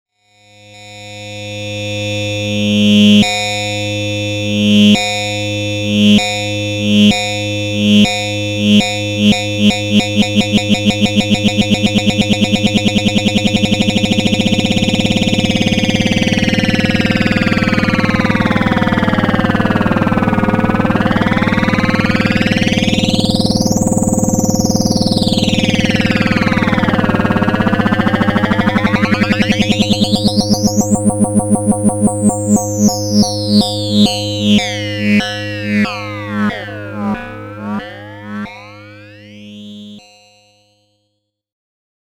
sample one: two oscillators on the inputs. two outputs from individual harmonics fed into a fader module with lfo control.